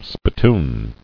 [spit·toon]